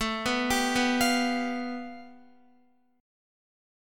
A7sus2#5 Chord
Listen to A7sus2#5 strummed